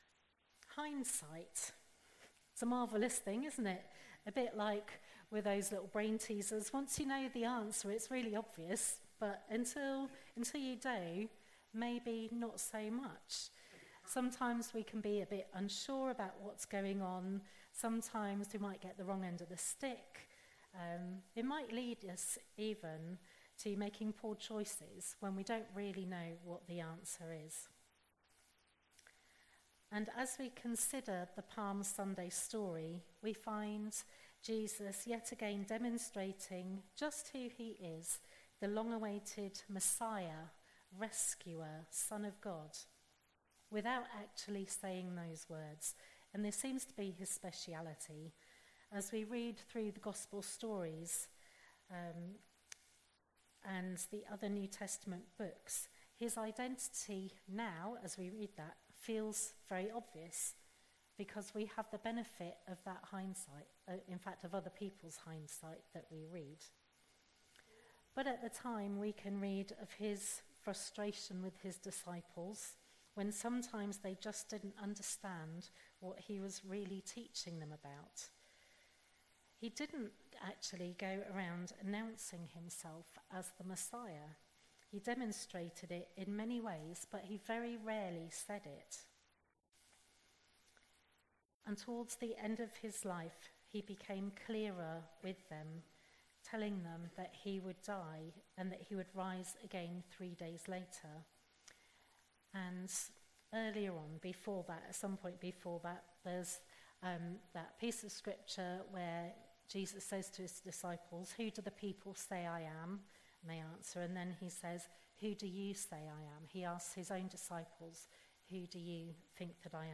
There is an audio version of the sermon also available but the sound level is quite low so you’ll have to turn your speakers up.
04-13-sermon.mp3